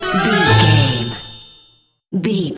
Ionian/Major
DOES THIS CLIP CONTAINS LYRICS OR HUMAN VOICE?
WHAT’S THE TEMPO OF THE CLIP?
orchestra
strings
flute
drums
violin
circus
goofy
comical
cheerful
perky
Light hearted
quirky